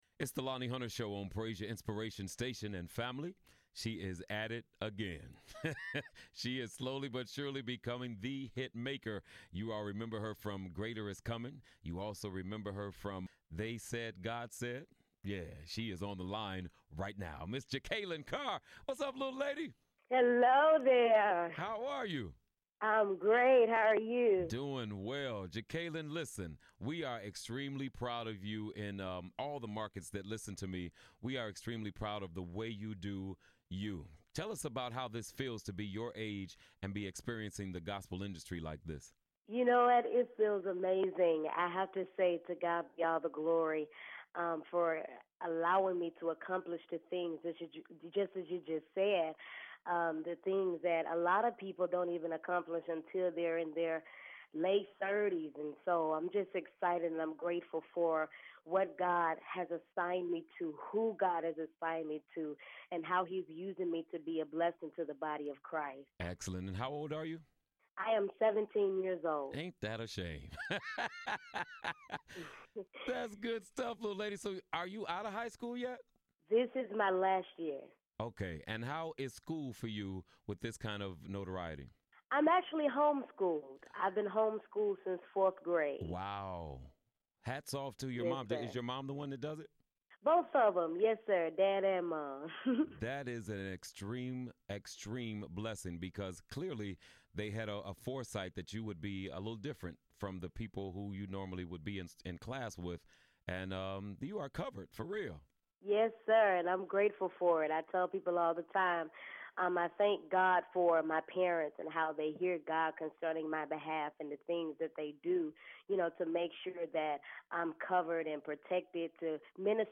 talks to gospel songtress